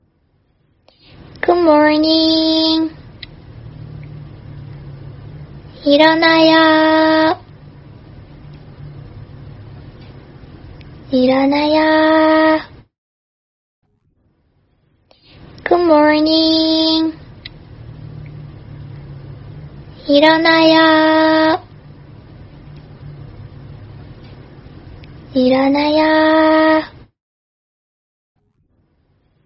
nada dering alarm bangun tidur korea [download]
nada-alarm-bangun-tidur-korea-id-technolati_com.mp3